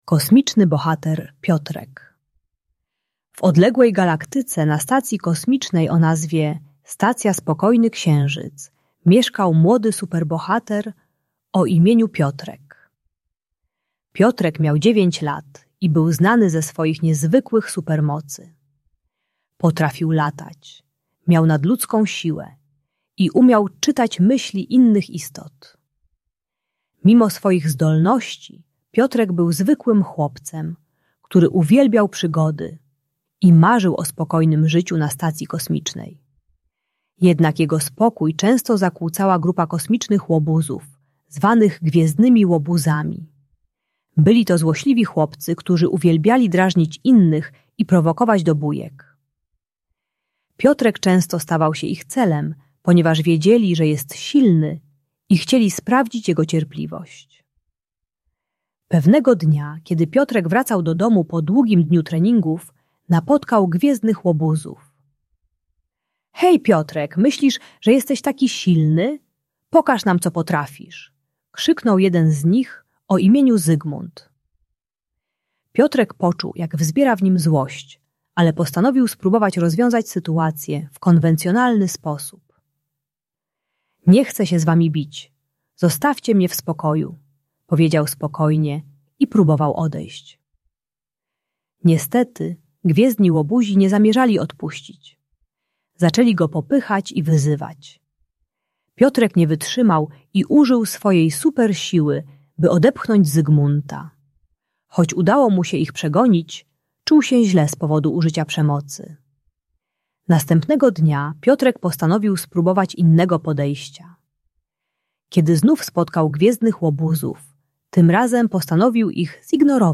Kosmiczny Bohater Piotrek - Bunt i wybuchy złości | Audiobajka